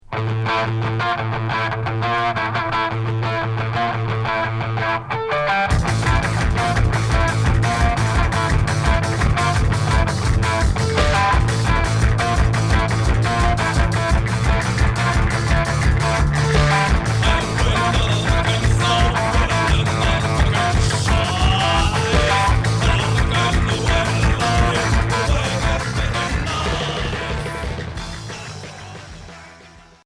Hardcore Unique Maxi 45t